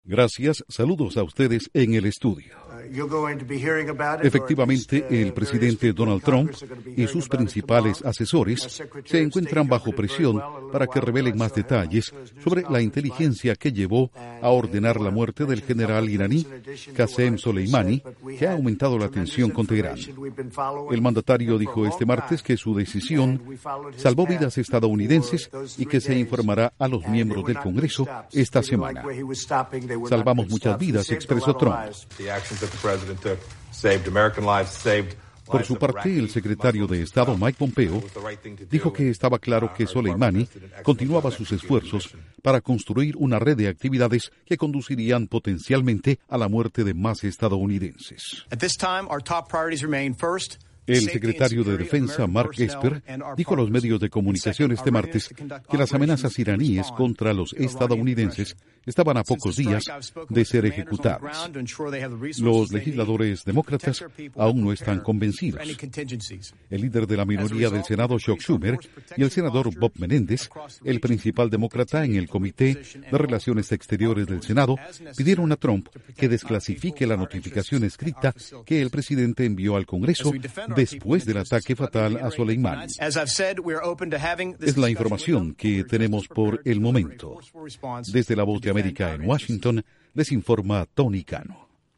Duración: 1:31 Declaraciones de Trump, Pompeo y Esper Reacción de demócratas